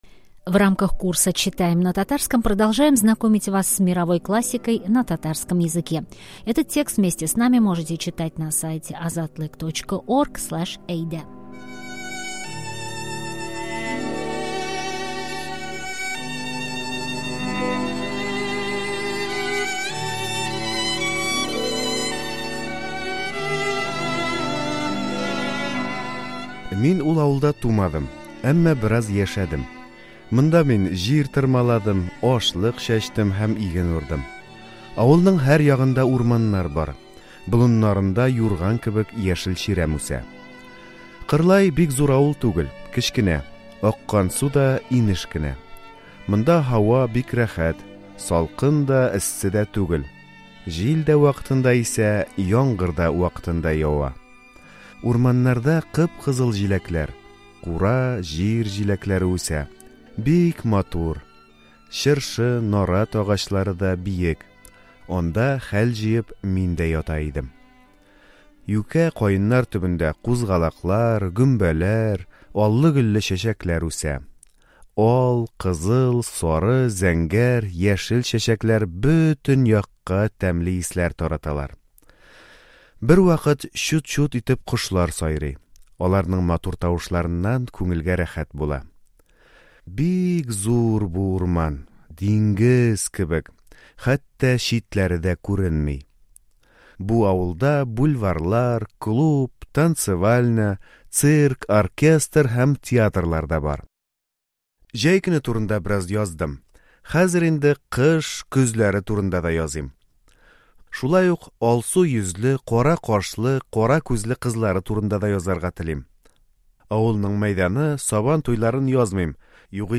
Перевод ключевых фраз и аудиосказка прилагается.